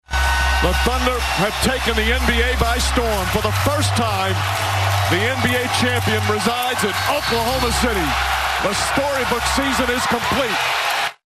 One of the final calls as heard on Sunday night on ABC.